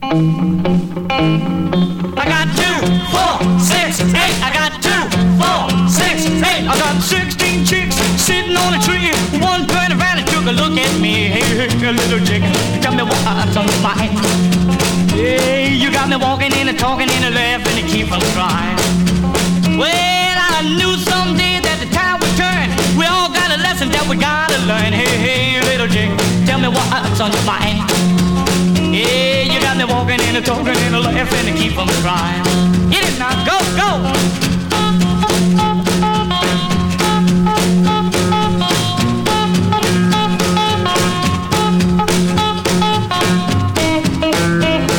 Rock & Roll, Rockabilly　Germany　12inchレコード　33rpm　Mono